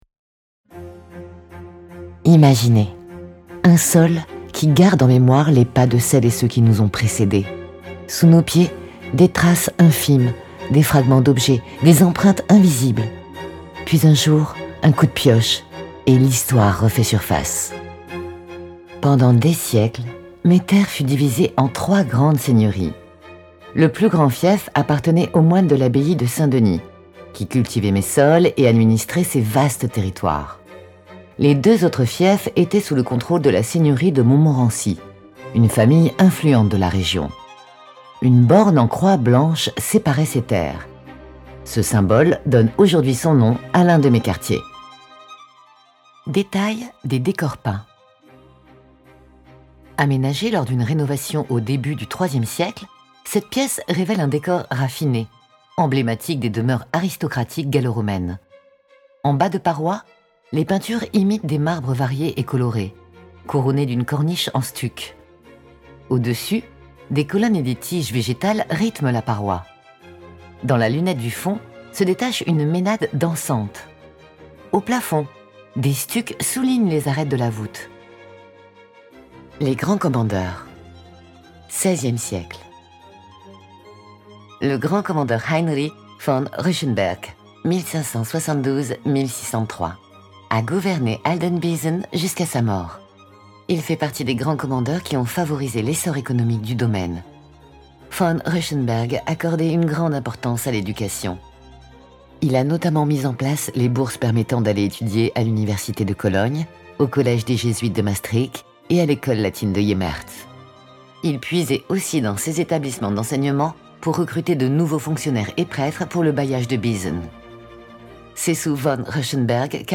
Natural, Llamativo, Cálida
Audioguía